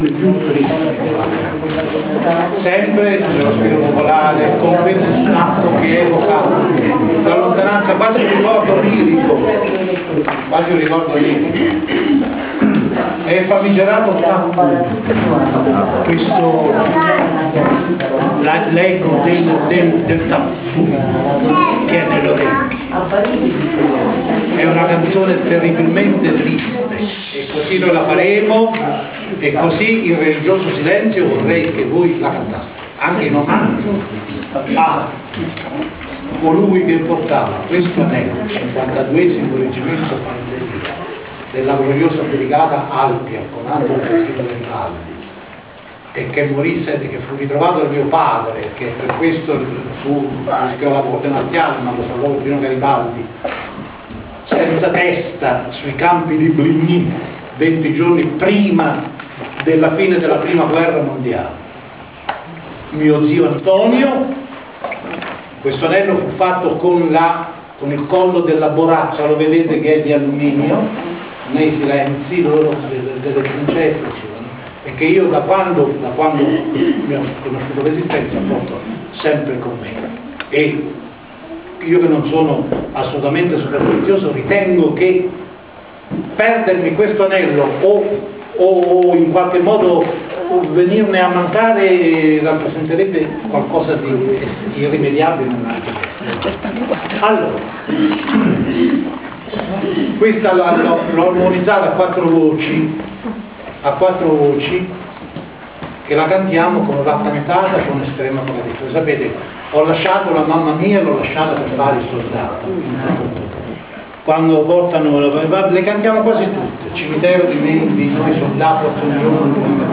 Civico Museo d’Arte Moderna di Anticoli Corrado, sabato 13 ottobre 2007